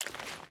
Water Walk 1.ogg